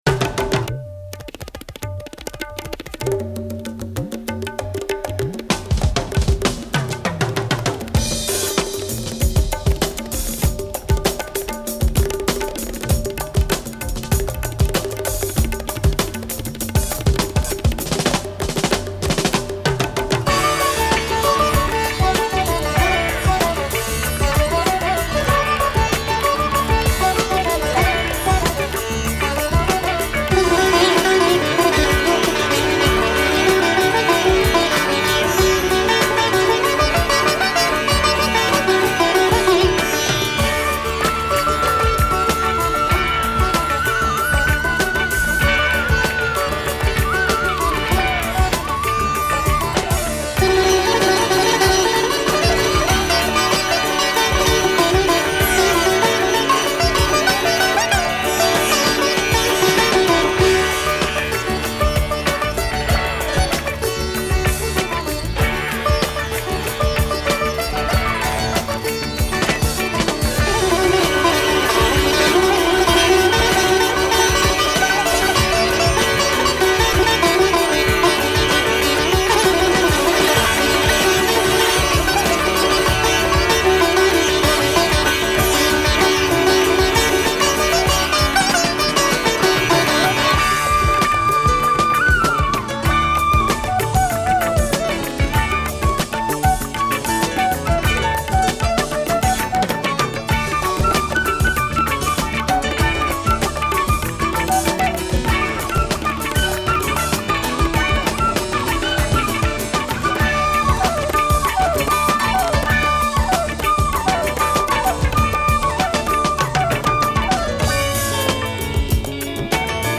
keyboard
saxophone
flute
drums